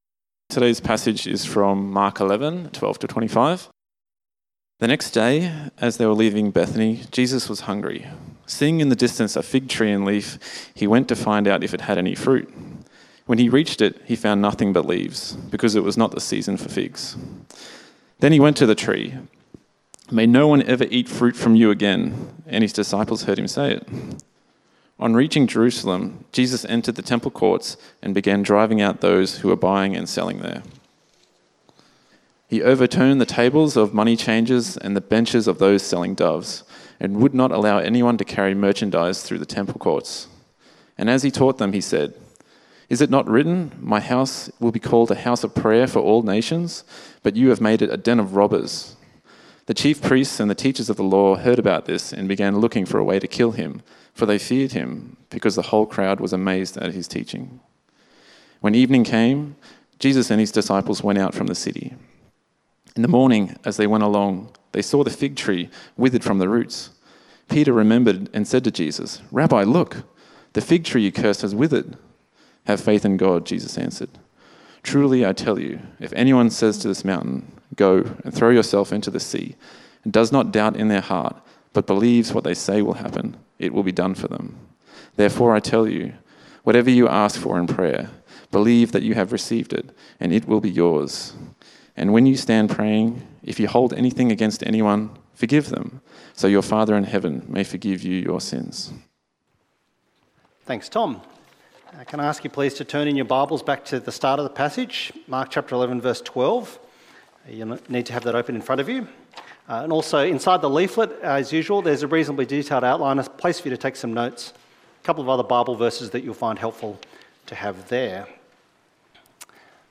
Meet Jesus Sermon outline